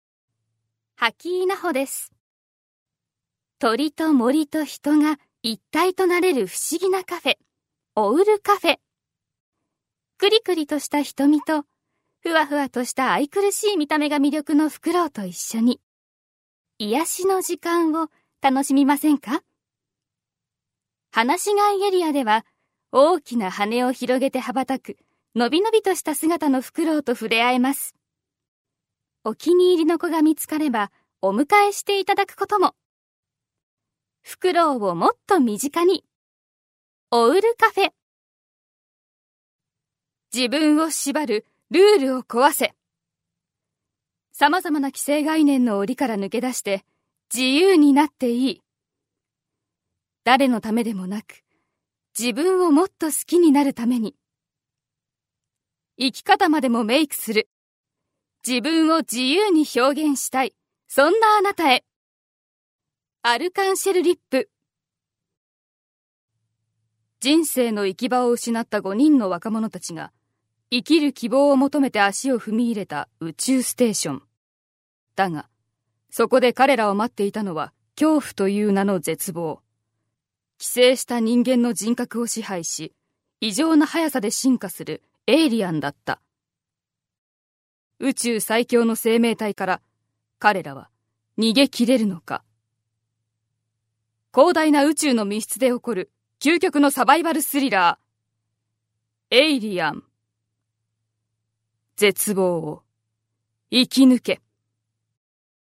Voice Sample ナレーション